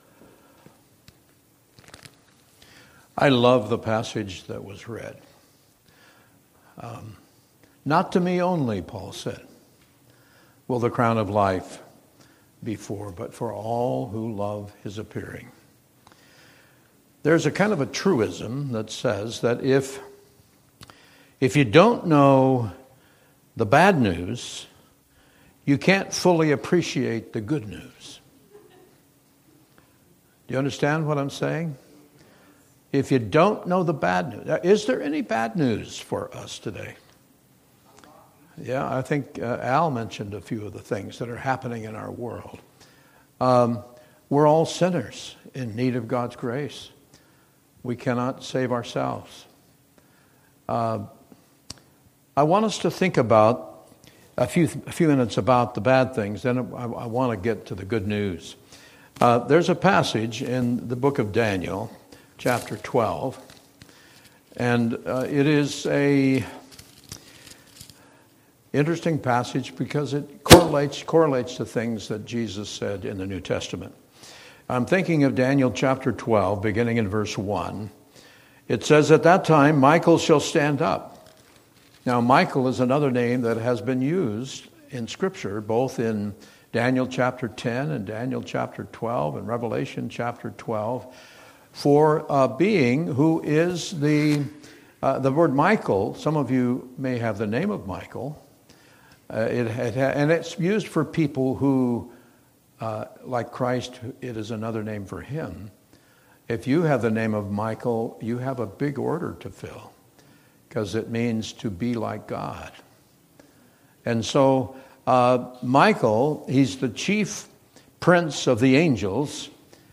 Passage: 2 Timothy 4:5-8 Service Type: Worship Service